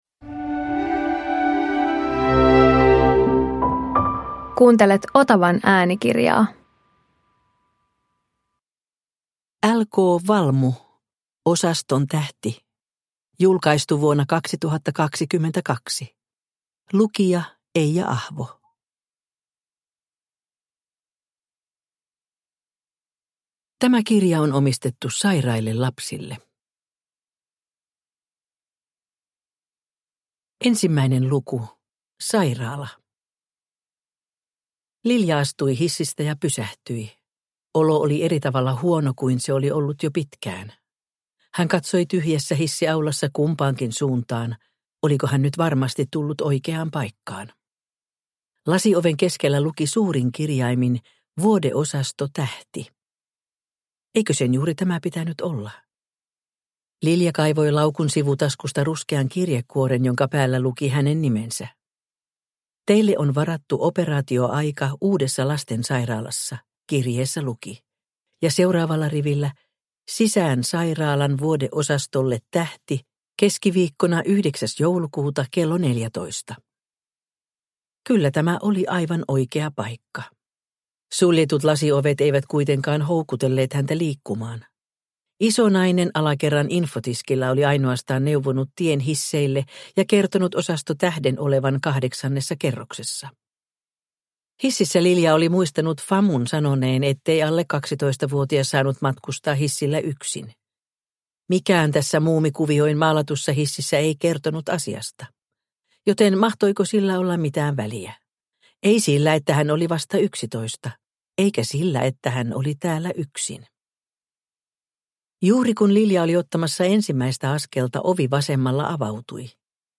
Osaston tähti – Ljudbok – Laddas ner